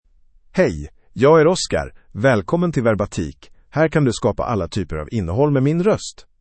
MaleSwedish (Sweden)
OscarMale Swedish AI voice
Oscar is a male AI voice for Swedish (Sweden).
Voice sample
Listen to Oscar's male Swedish voice.
Oscar delivers clear pronunciation with authentic Sweden Swedish intonation, making your content sound professionally produced.